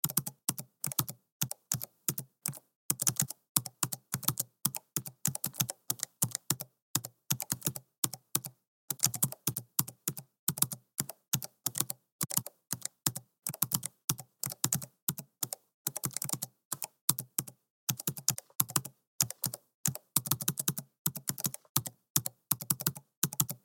جلوه های صوتی
دانلود صدای تایپ 4 از ساعد نیوز با لینک مستقیم و کیفیت بالا
برچسب: دانلود آهنگ های افکت صوتی اشیاء دانلود آلبوم صدای تایپ کردن از افکت صوتی اشیاء